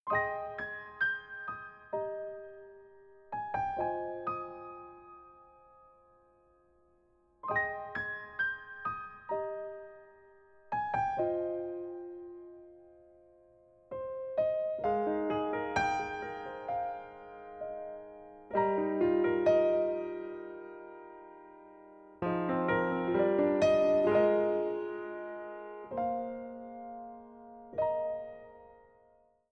failsound.mp3